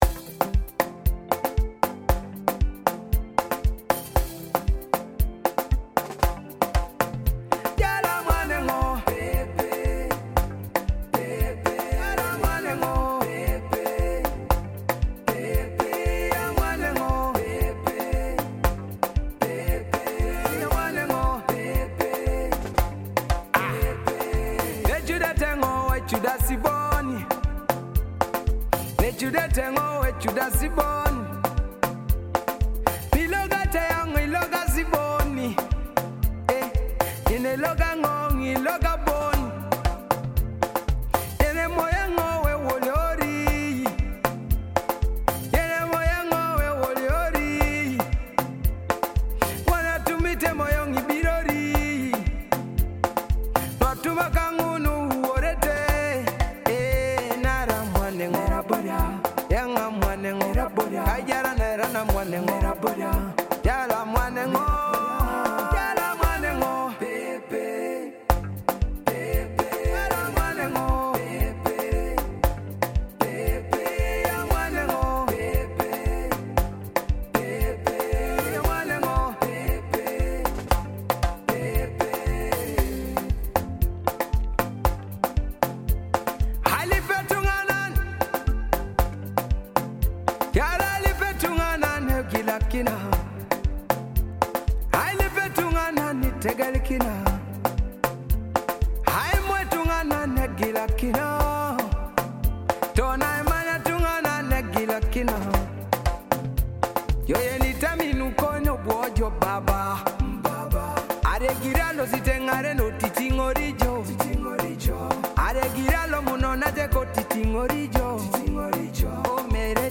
Afrobeat single